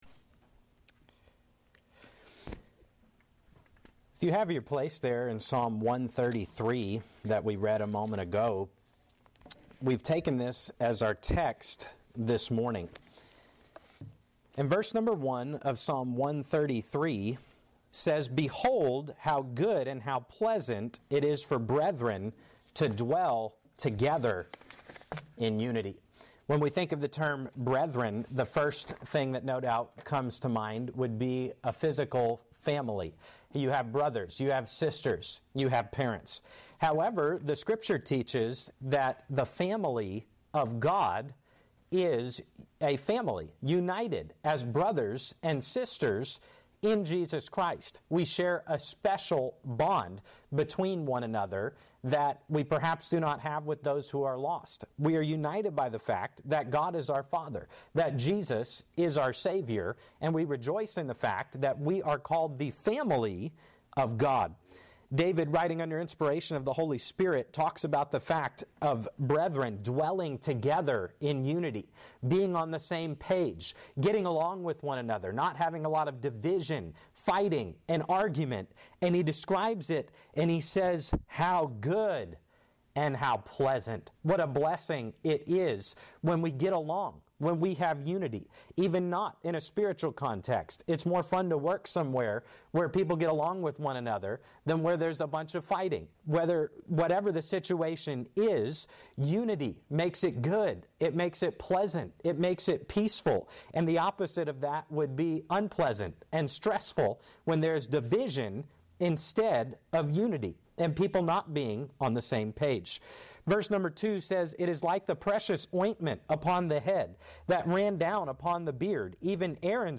Sermons | Renewal Baptist Church